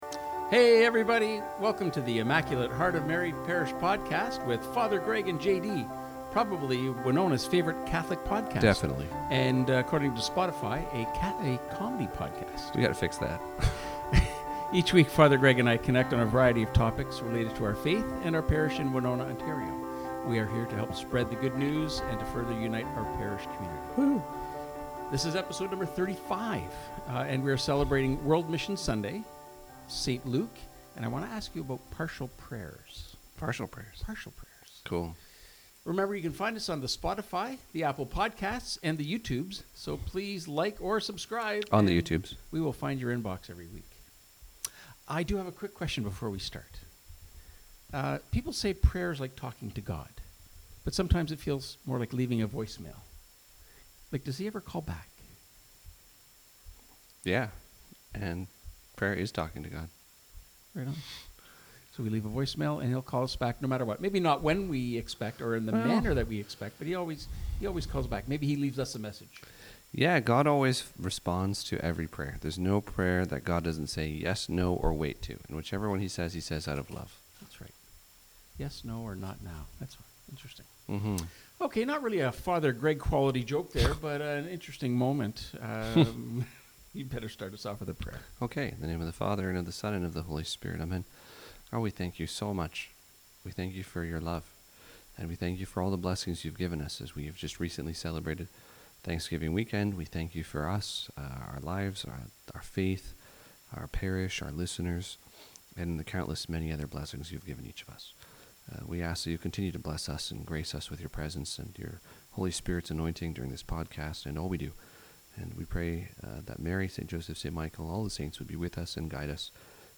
We are here to help spread the good news and help to further unite our parish community.